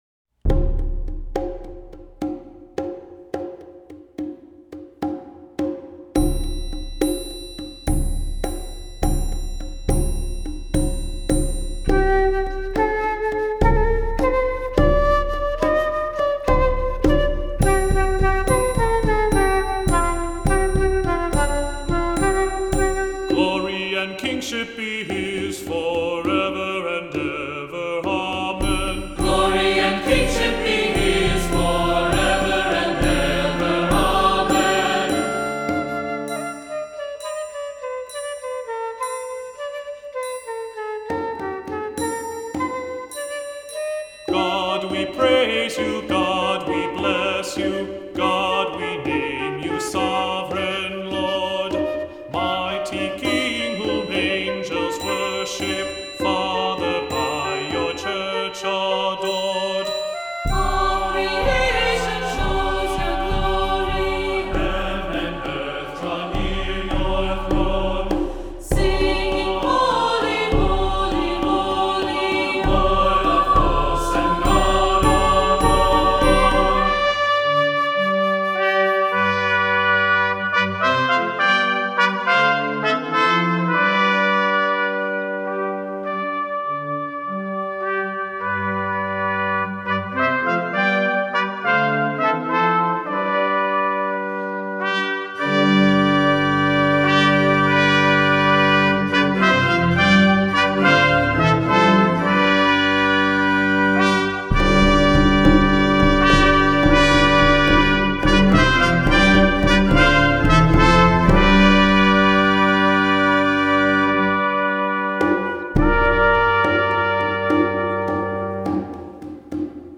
Voicing: "SATB, Descant, Assembly"